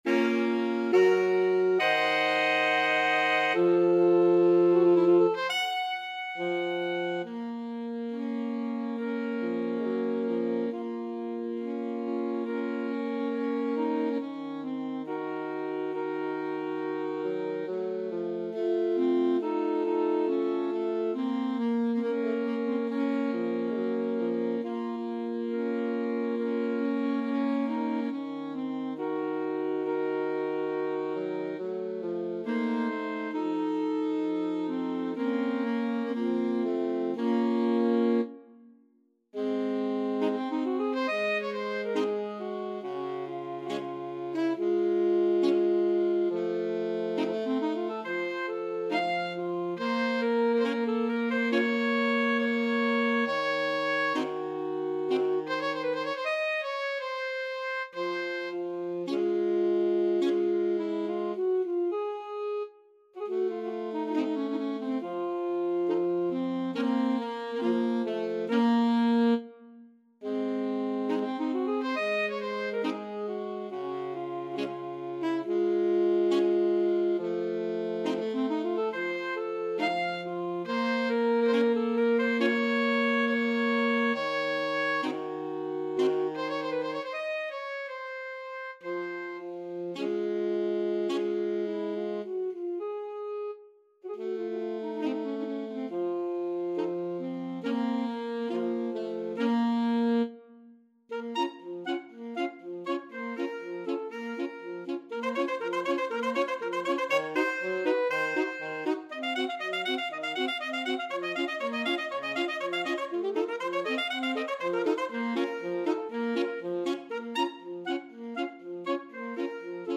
2/4 (View more 2/4 Music)
Largo = c.69
Classical (View more Classical Alto Sax Quartet Music)